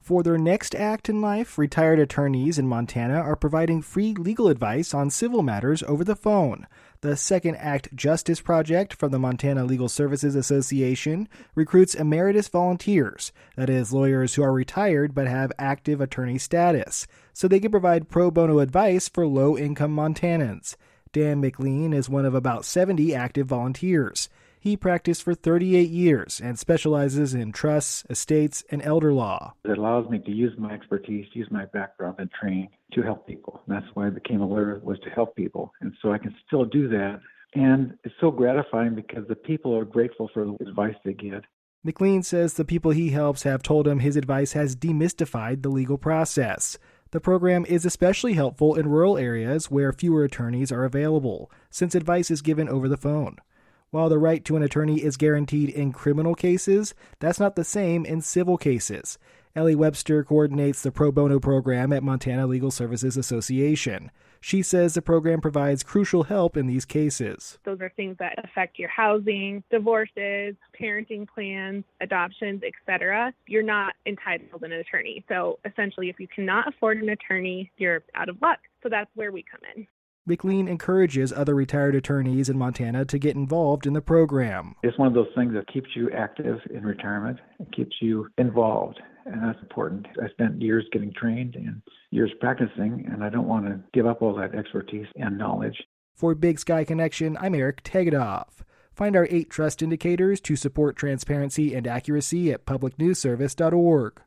Big Sky Connection - A program in Montana hooks up retired attorneys with people who need advice on civil legal matters. The program is aimed at helping people who can't afford an attorney. Comments